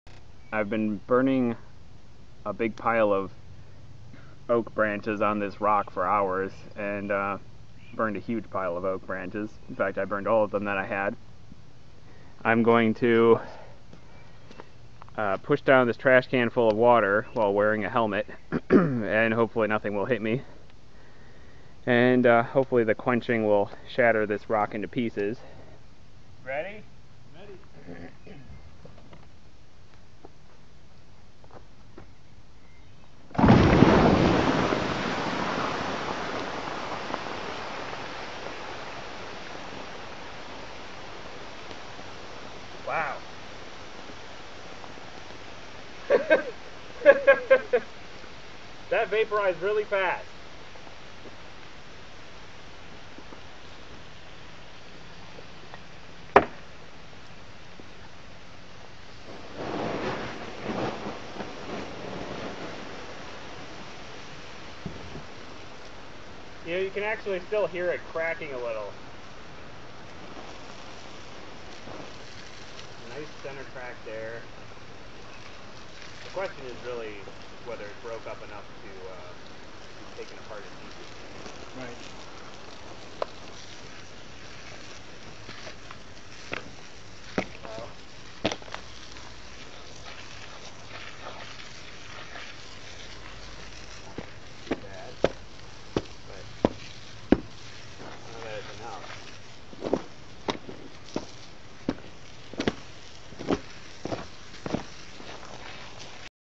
Mineração subterrânea com dinamite
Mapa-8-Mineracao-subterranea.mp3